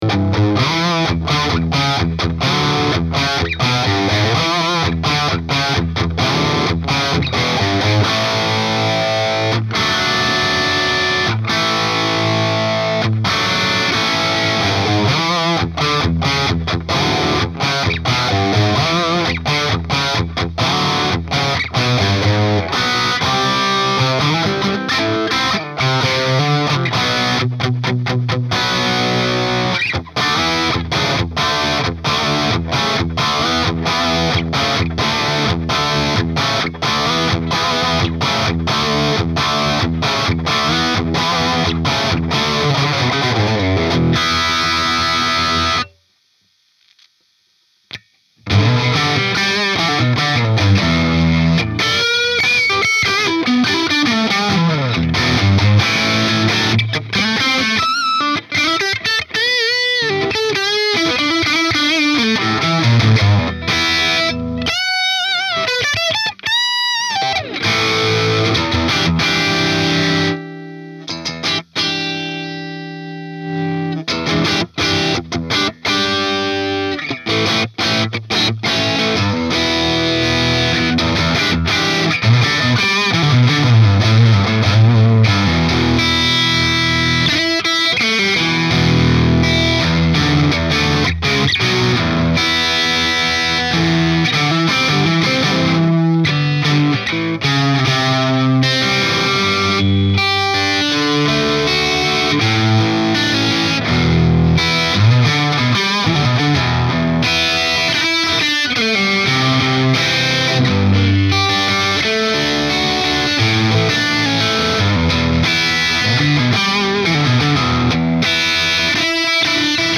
Softube - Kerry King Sign + IR.mp3